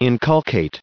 884_inculcate.ogg